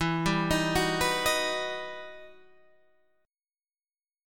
E Minor Major 9th